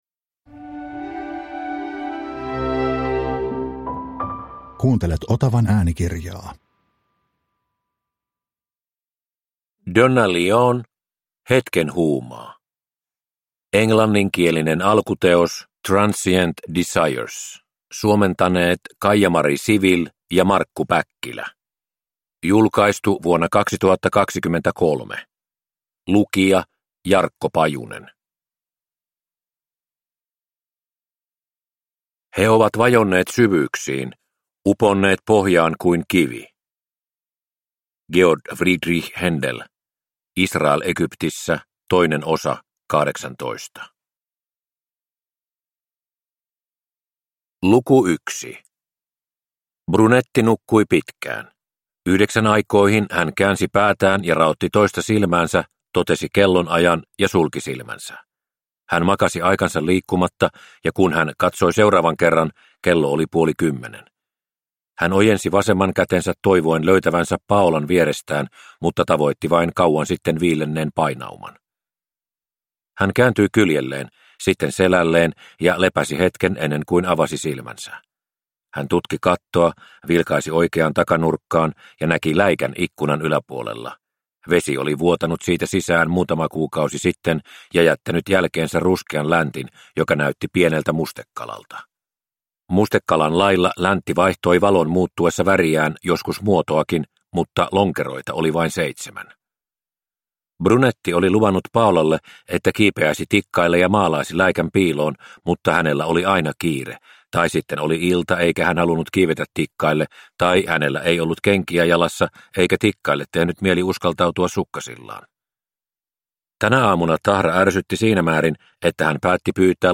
Hetken huumaa – Ljudbok – Laddas ner